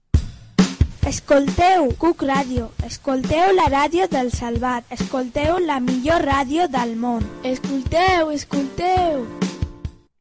Identificació de la ràdio